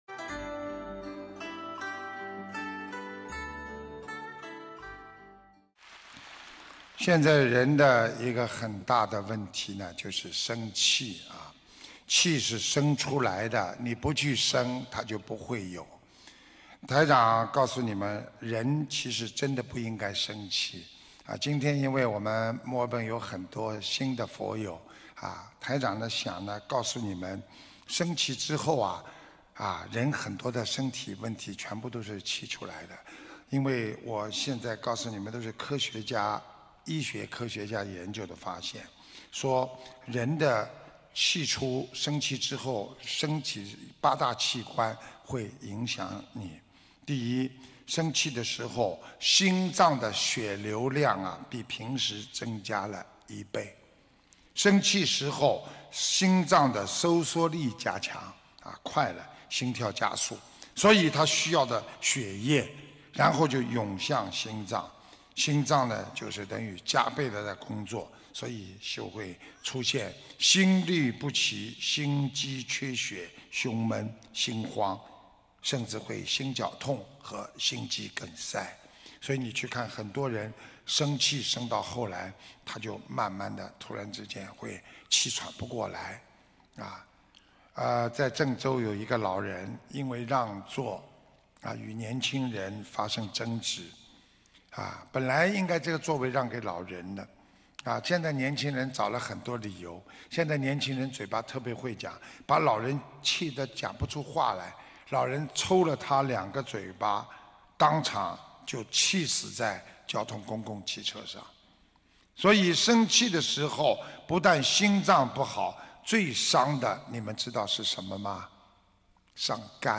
视频：68_澳大利亚·墨尔本《玄藝综述》解答会 2017年11月12日 节选（四） - 法会节选 百花齐放